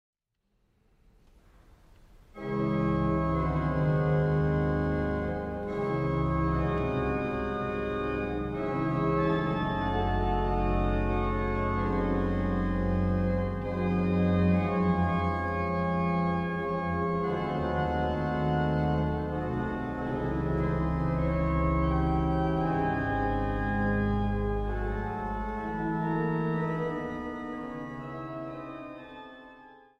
klassieke werken en eigen orgelimprovisaties.